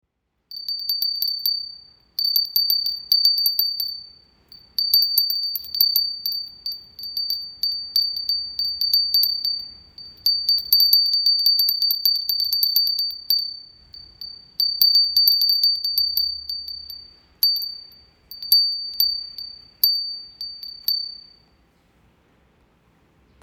磁器風鈴は1つ１つ音色が違います。
風鈴の音色を確認できます
七宝透かし虹彩磁風鈴 冬山窯